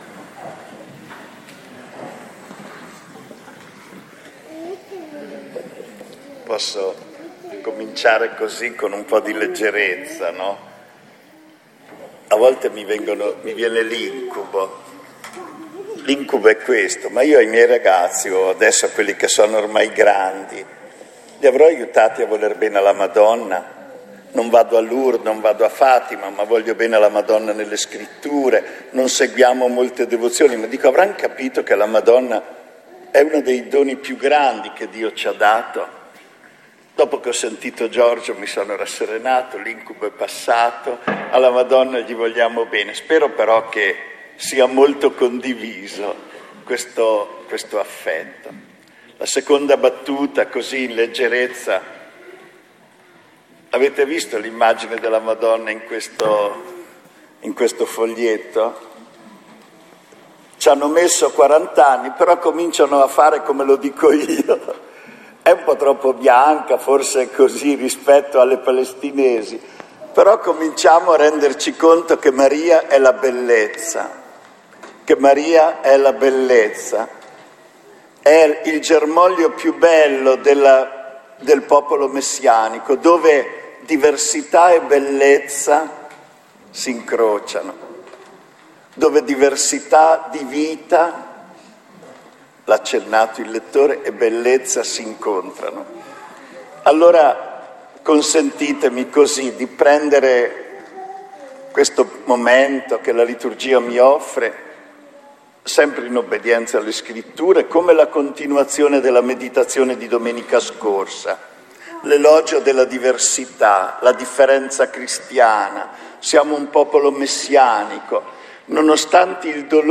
Omelia
in occasione della festa dell’Immacolata.